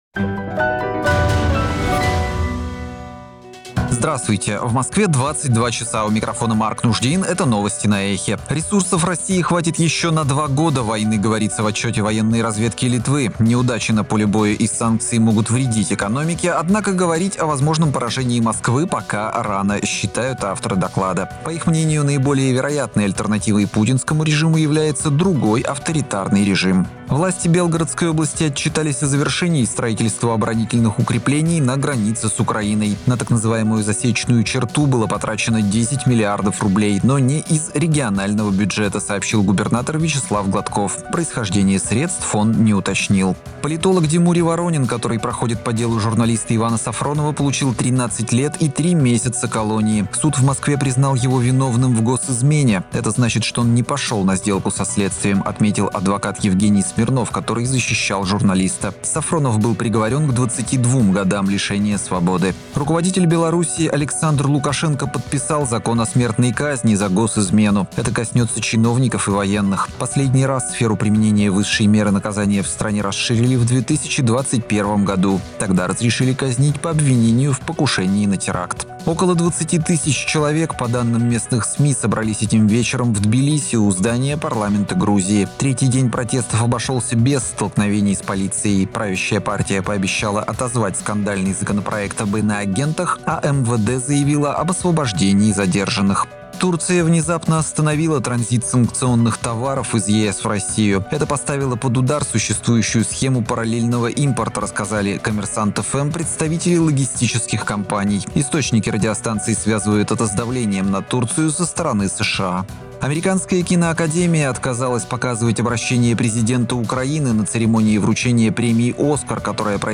Слушайте свежий выпуск новостей «Эха»…